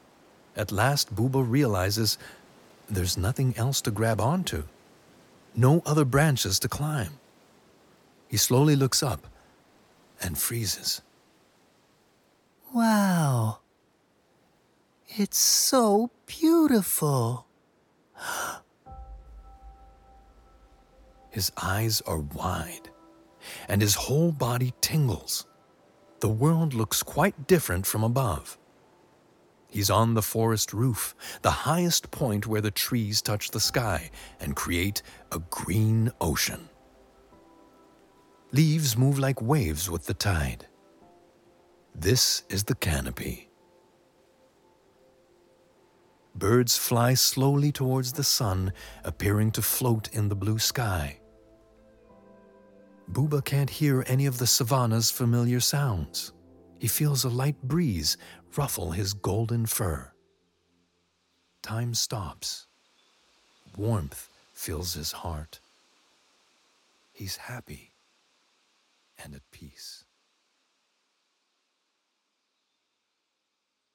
Narration - EN